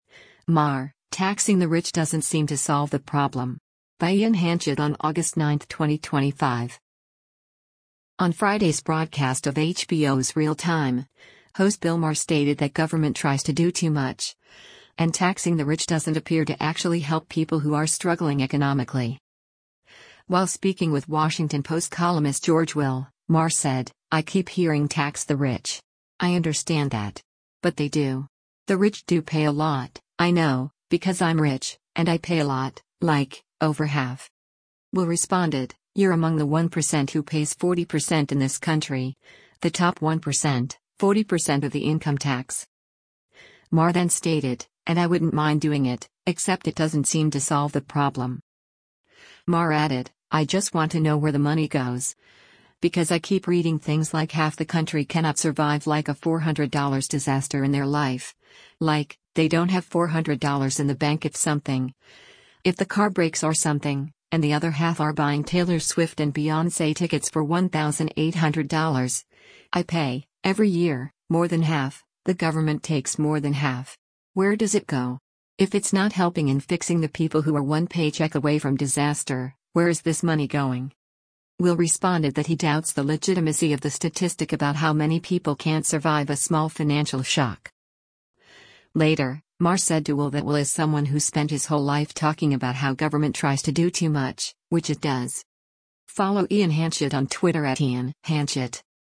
On Friday’s broadcast of HBO’s “Real Time,” host Bill Maher stated that “government tries to do too much,” and taxing the rich doesn’t appear to actually help people who are struggling economically.